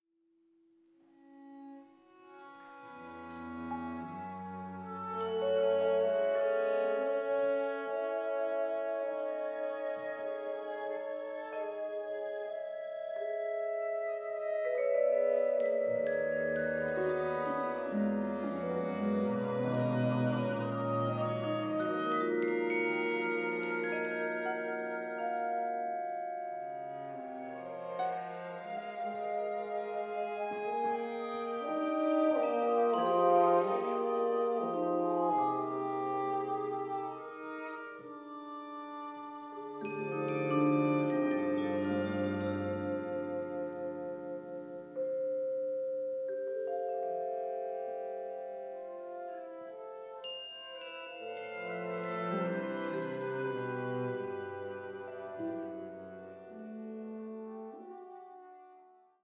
for chamber ensemble